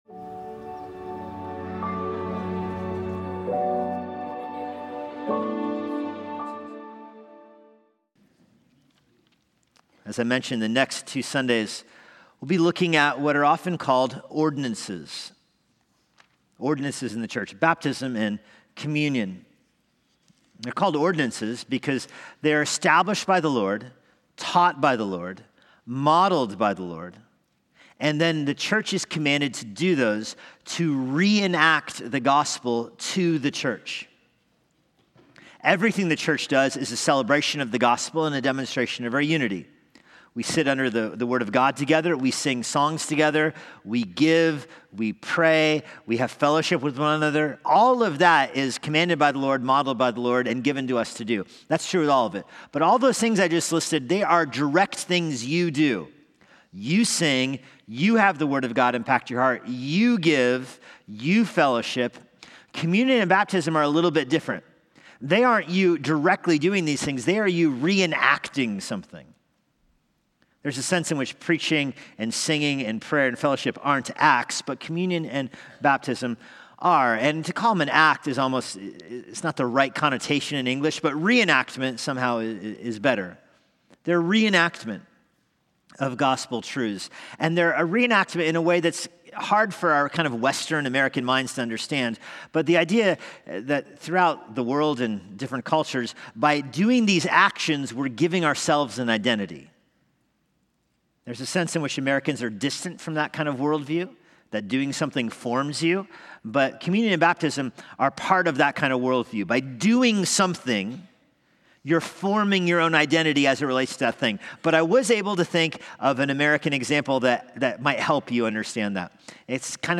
Relevant teaching for today from Immanuel Bible Church.